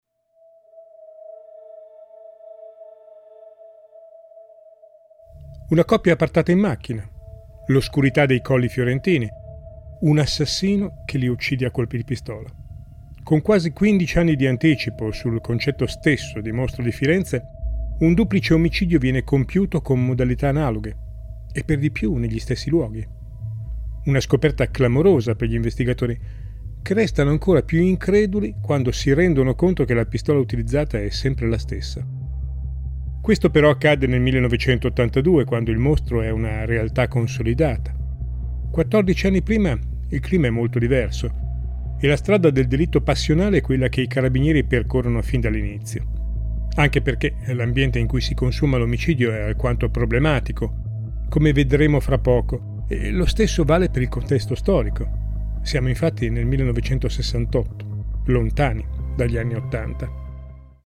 Musiche originali Operà Music